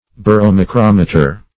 Meaning of baromacrometer. baromacrometer synonyms, pronunciation, spelling and more from Free Dictionary.
Search Result for " baromacrometer" : The Collaborative International Dictionary of English v.0.48: Baromacrometer \Bar`o*ma*crom"e*ter\, n. [Gr. ba`ros weight + makro`s long + -meter.] (Med.) An instrument for ascertaining the weight and length of a newborn infant.